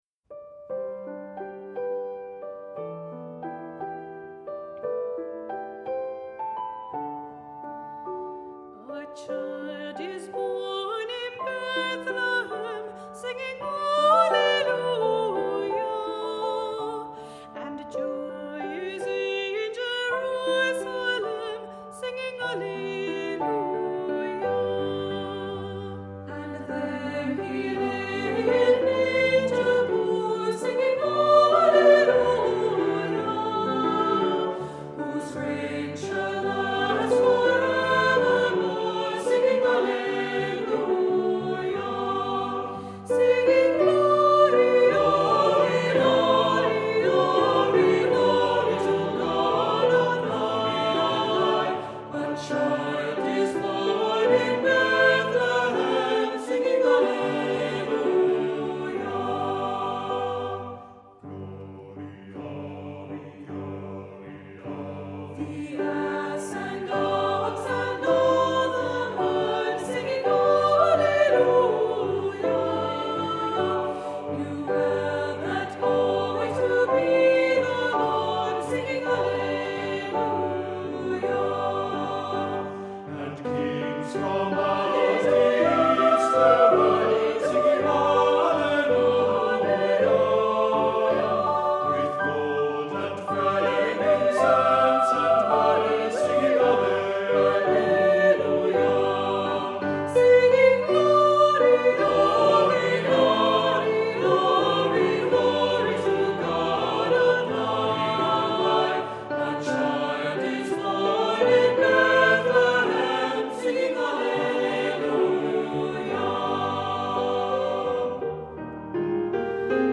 Accompaniment:      Piano
Music Category:      Choral
Percussion part is for finger cymbals and wind chimes